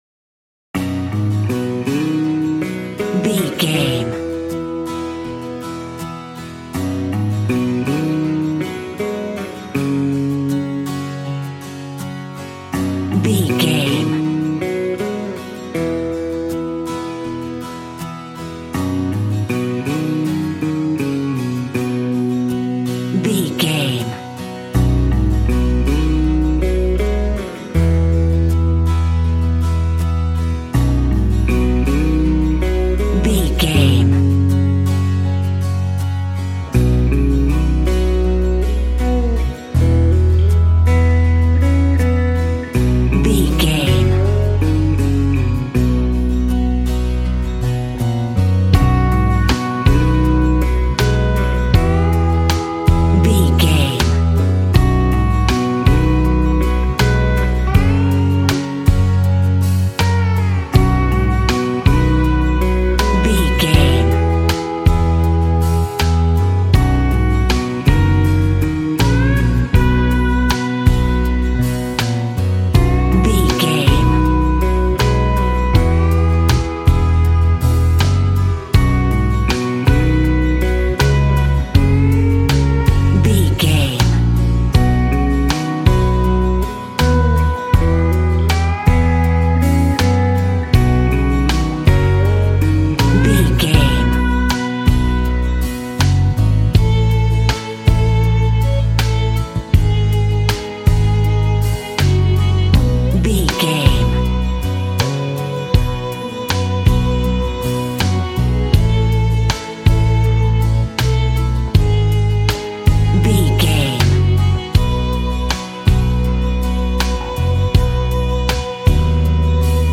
Ionian/Major
romantic
acoustic guitar
bass guitar
drums